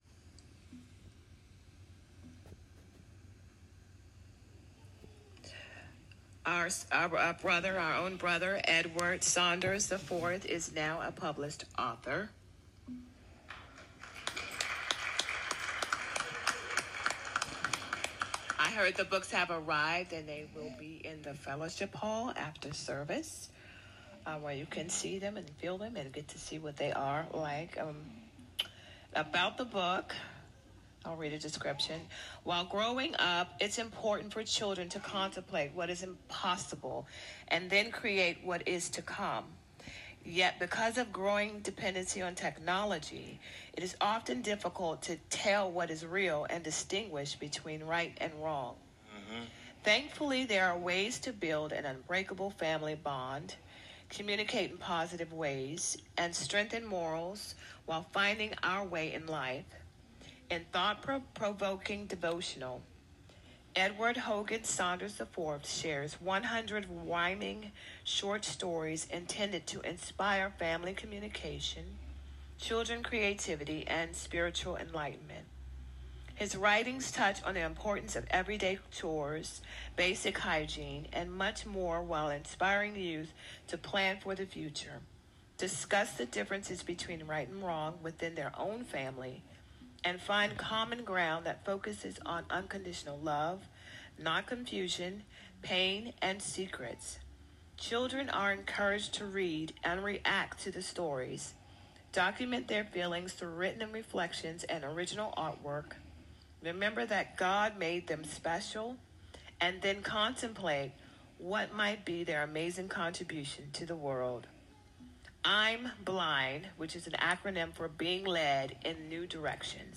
Presentation at the Greater Revelation Baptist Church